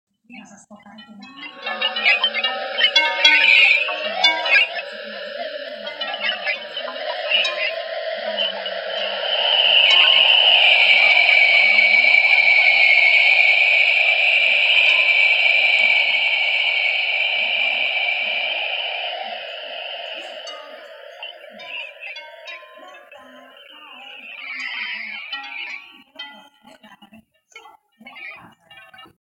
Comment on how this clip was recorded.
Although the sonification was not recorded in soace ,scientists are able to export a sonification after thorough analyzations on the colors ,rays etc of the image .